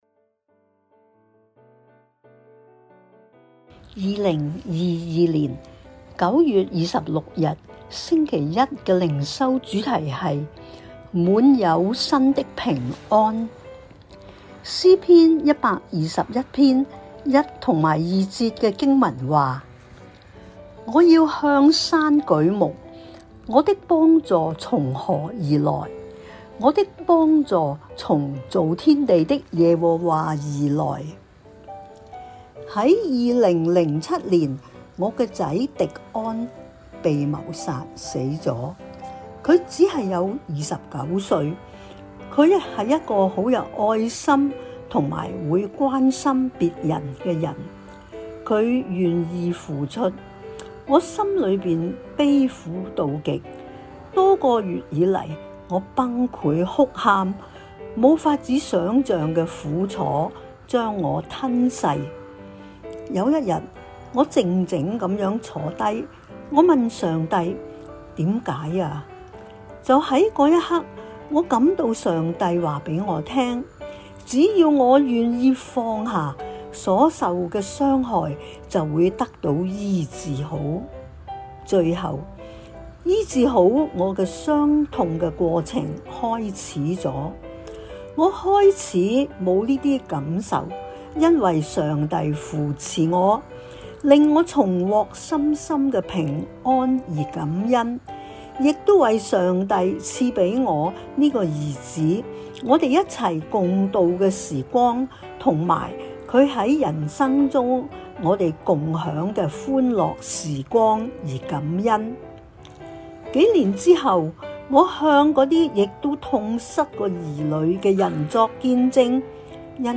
循道衞理聯合教會香港堂 · 錄音佈道組 Methodist Outreach Programme